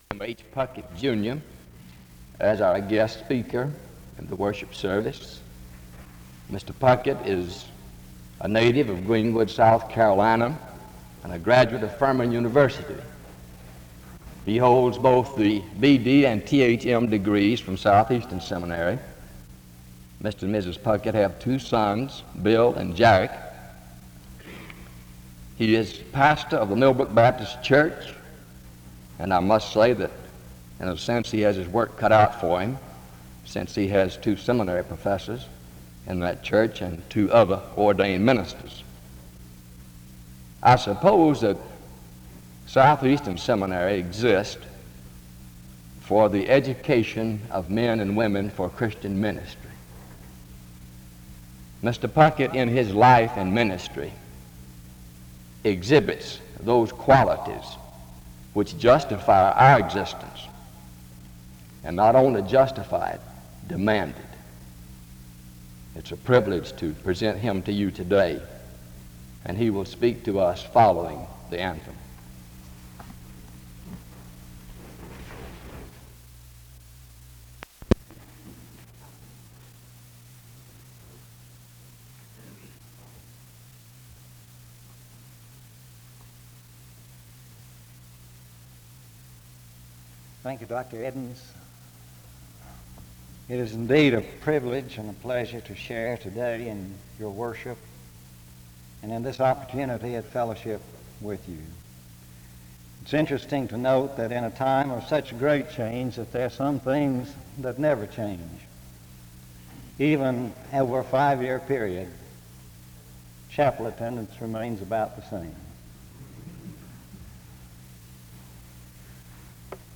The service opens with an introduction to the speaker from 0:00-1:12.
SEBTS Chapel and Special Event Recordings SEBTS Chapel and Special Event Recordings